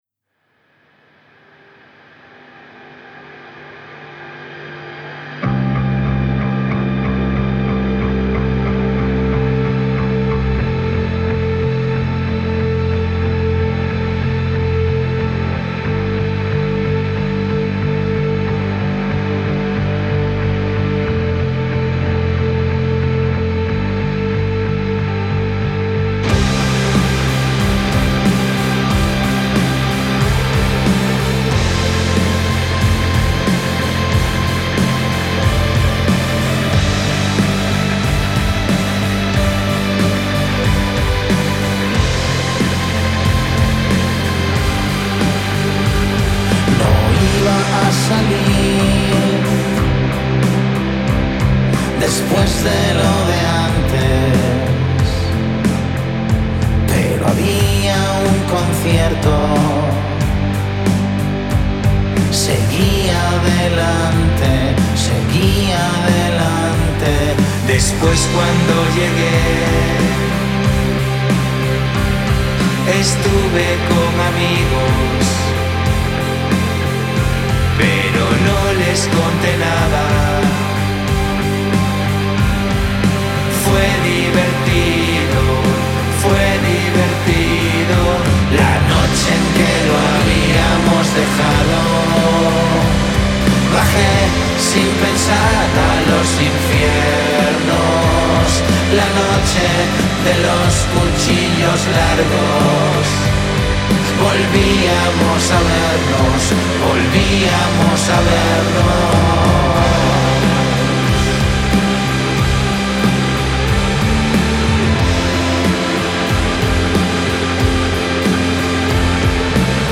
La esencia shoegaze y noise-pop característica